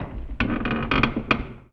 metal_low_creak_squeak_07.wav